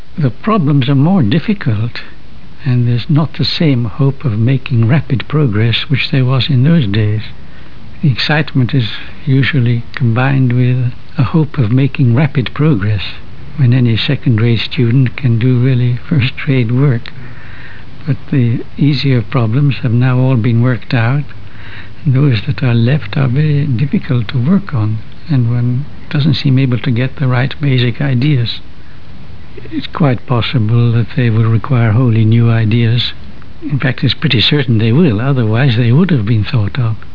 However, when the topic of beauty in physical theories was raised, Dirac began to speak with animation.